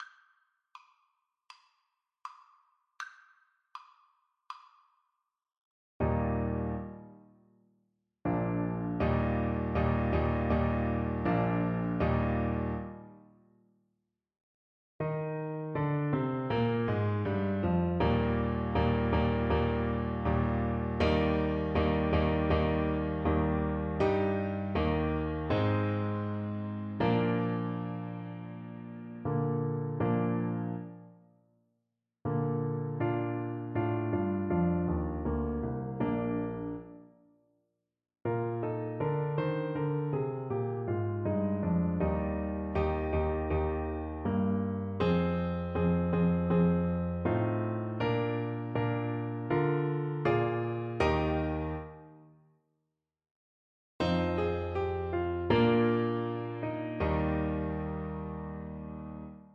2/2 (View more 2/2 Music)
Brightly = c. 60
Classical (View more Classical Clarinet Music)